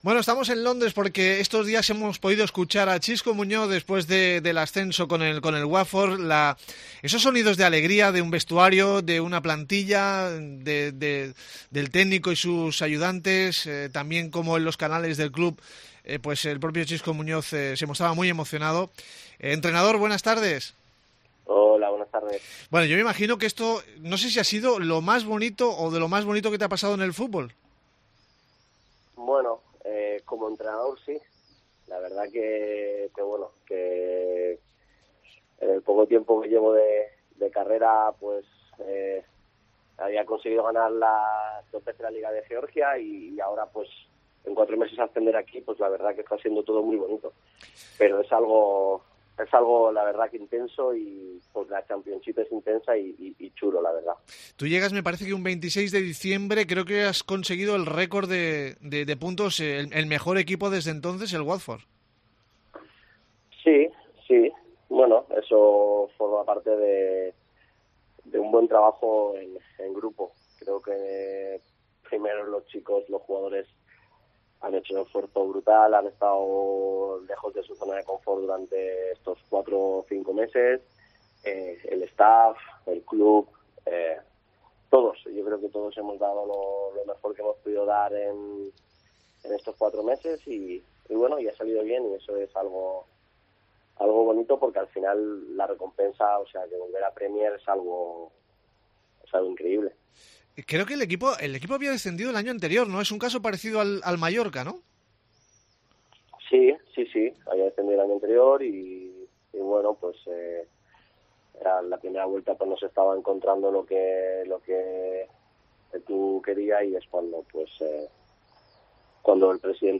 Entrevista con Xisco Muñoz, entrenador del Watford